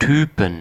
Ääntäminen
US : IPA : [ɡaɪ]